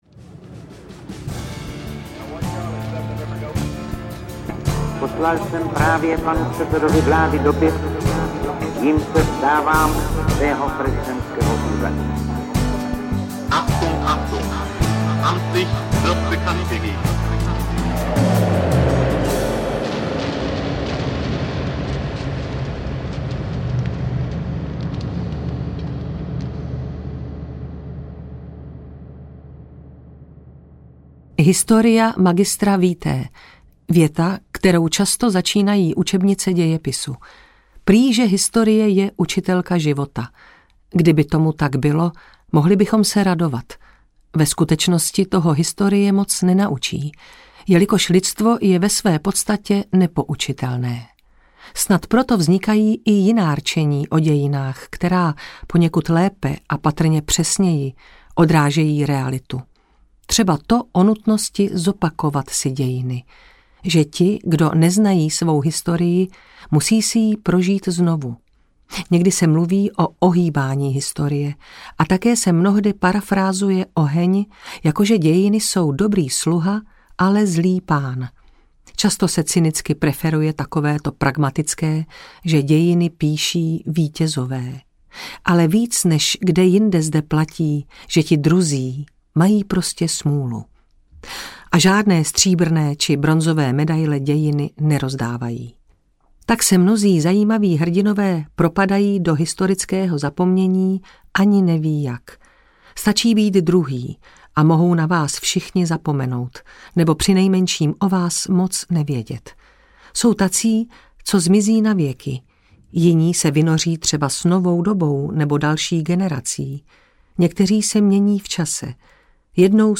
Doskočiště Protektorát 2 audiokniha
Ukázka z knihy
doskociste-protektorat-2-audiokniha